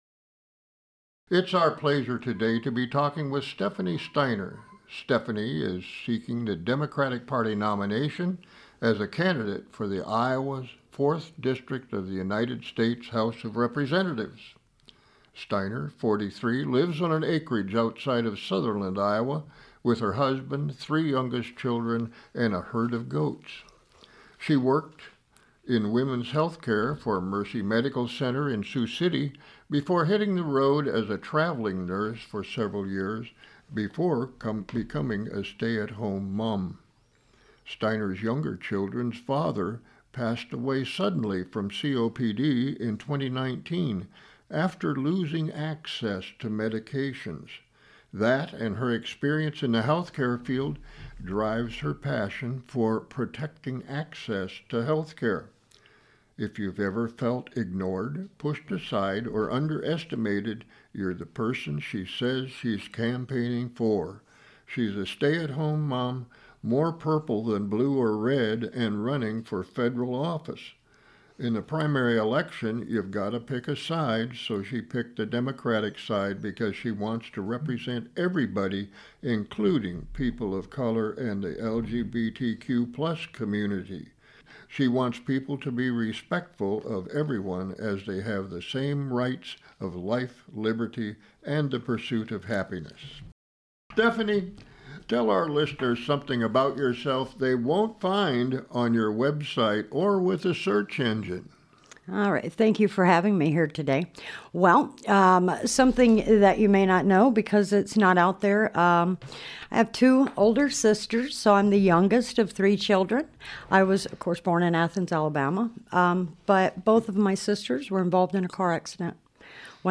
Conversations With Candidates is a pre-recorded interview show.